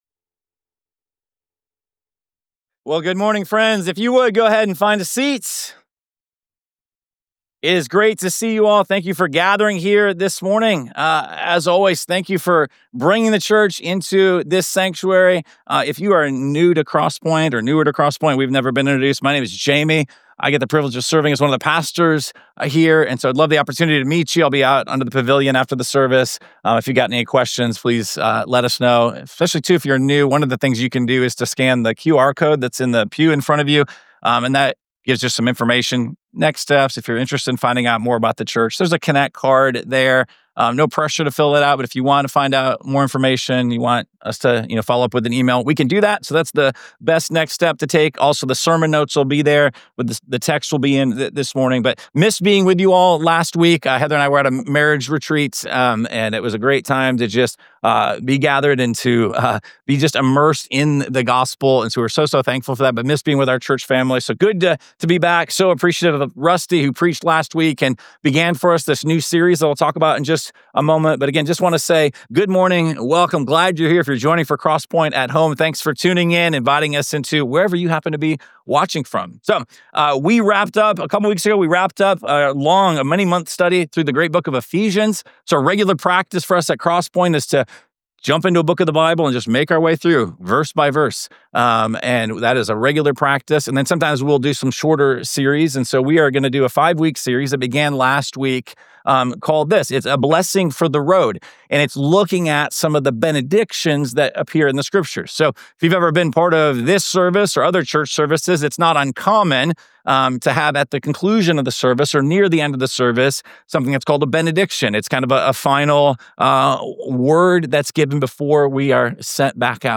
Sermon- Mastered. 7.20.25.mp3